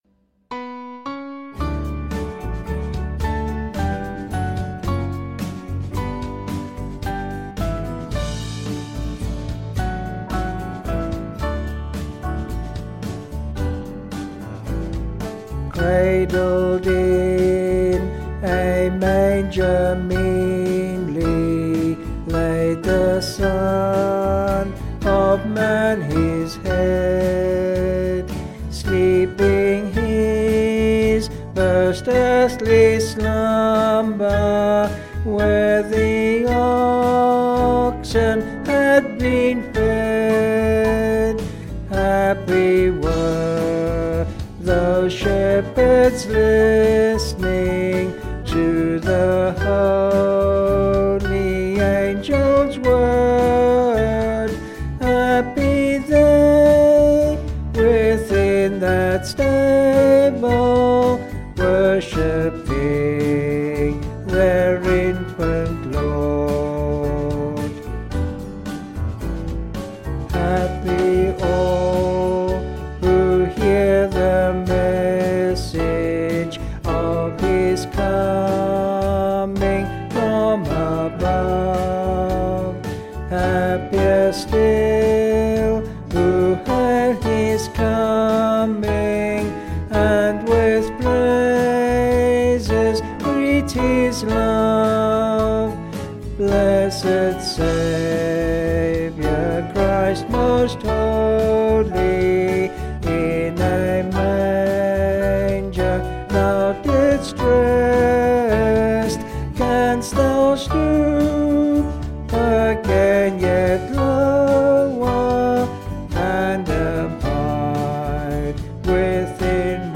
Vocals and Band   263.7kb Sung Lyrics